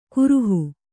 ♪ kuruhu